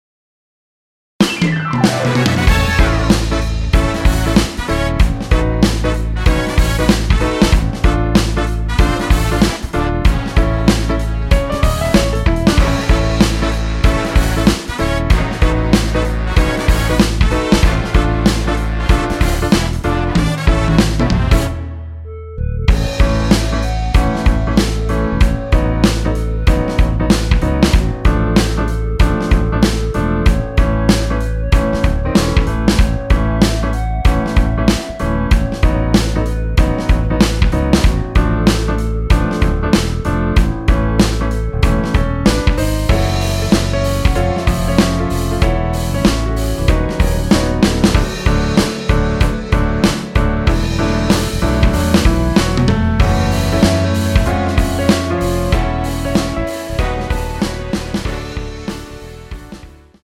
원키에서(-5)내린 멜로디 포함된 MR입니다.(미리듣기 확인)
앞부분30초, 뒷부분30초씩 편집해서 올려 드리고 있습니다.
중간에 음이 끈어지고 다시 나오는 이유는